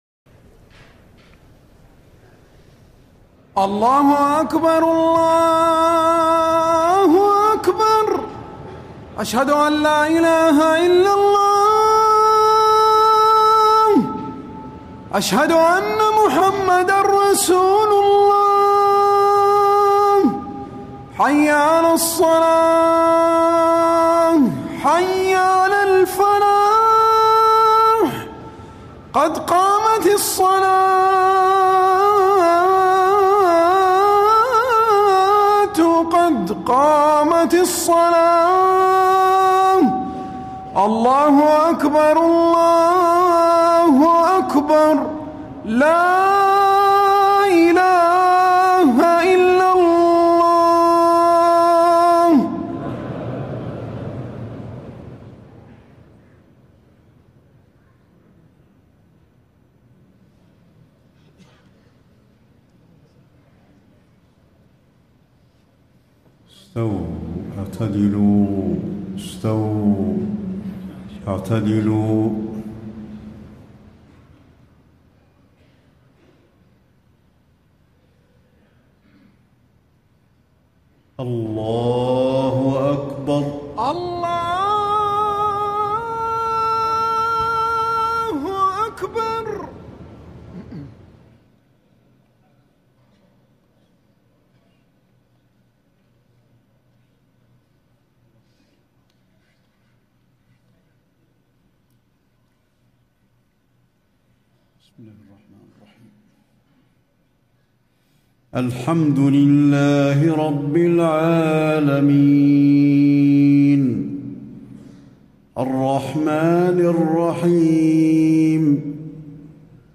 صلاة العشاء 5-2-1435 من سورة ق > 1435 🕌 > الفروض - تلاوات الحرمين